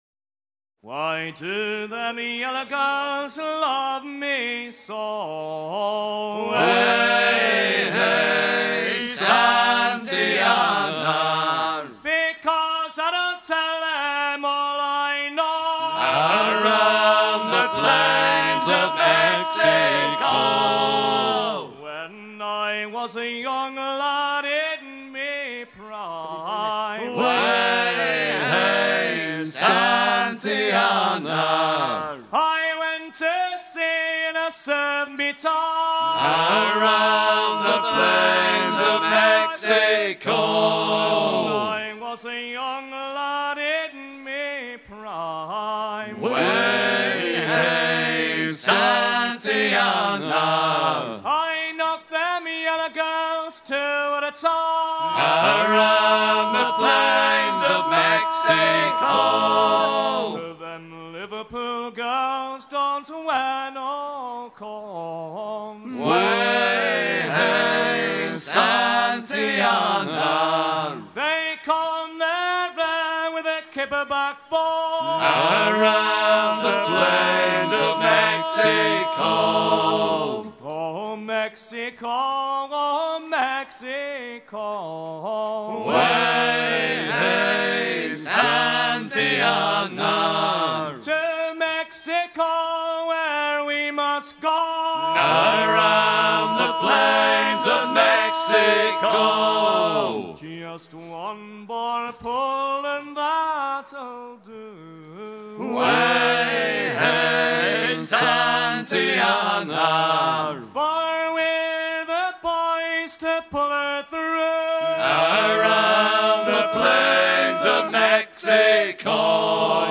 chanson satirique sur la pêche à la crevette de Volendam
circonstance : maritimes
Pièce musicale éditée